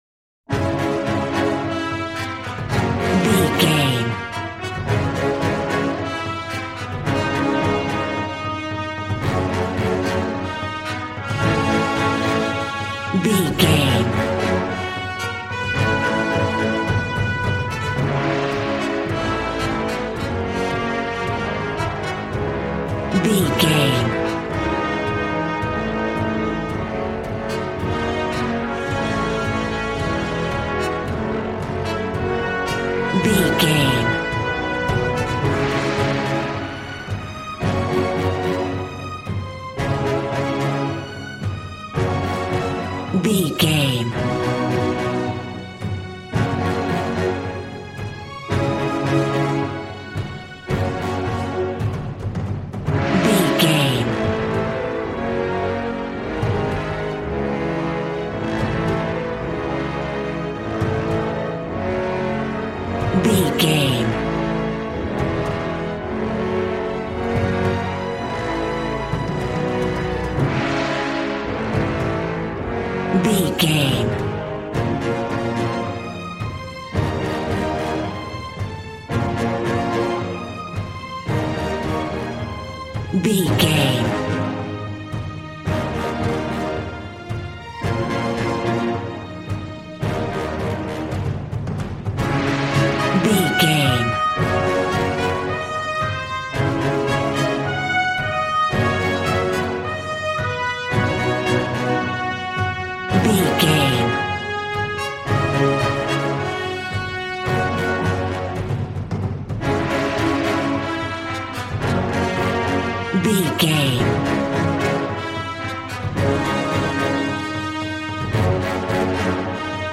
Aeolian/Minor
D♭
regal
cello
double bass